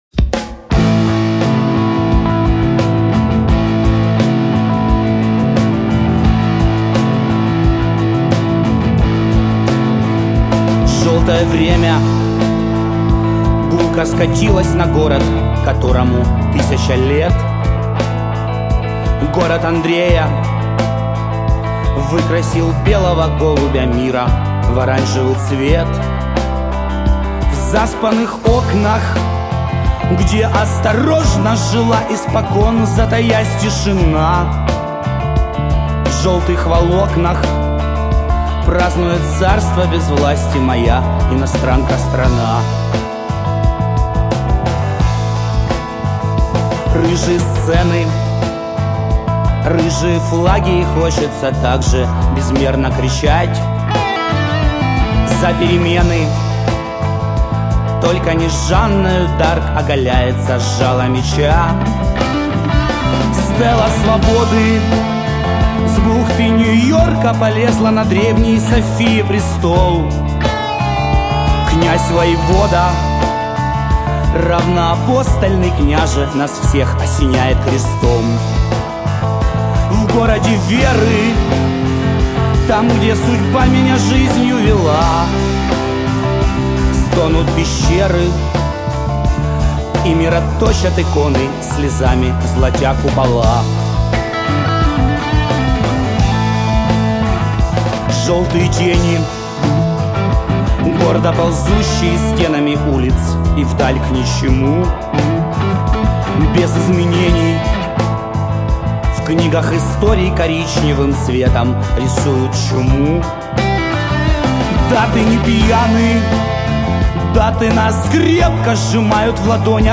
Там песня.